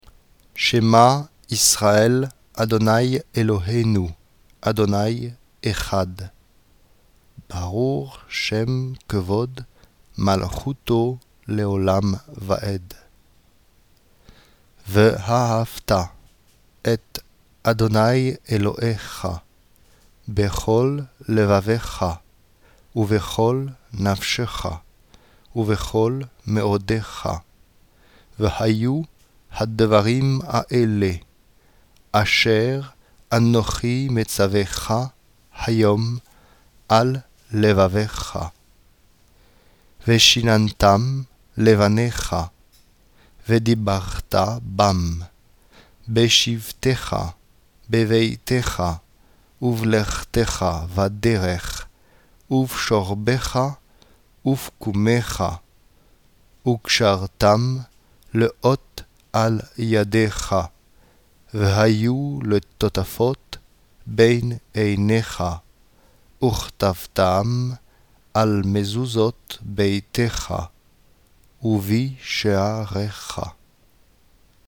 Pour permettre un meilleur apprentissage, nous vous proposons d’écouter les textes de Tefilati, lentement d’abord, chantés ensuite.
Premier paragraphe lent
20_shema1_lent.mp3